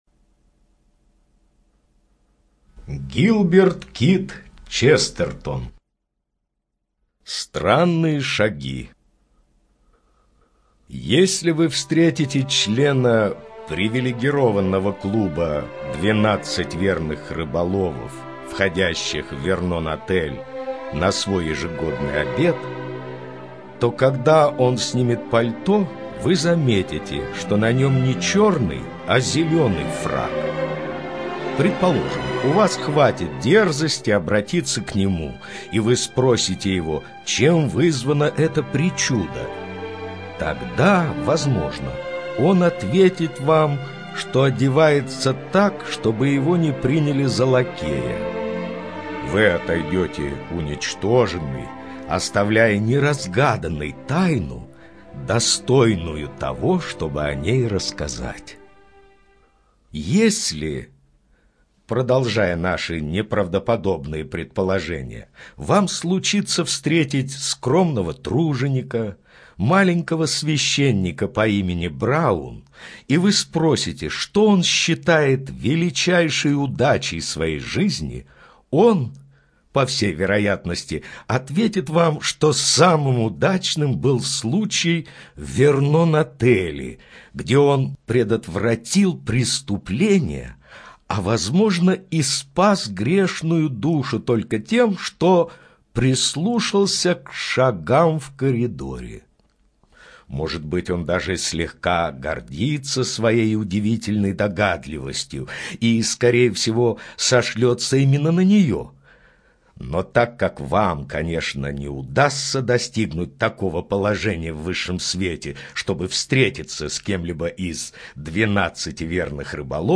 Аудиокнига посвящена творчеству выдающегося английского писателя, публициста и философа Гилберта Кийта Честертона (1874-1936).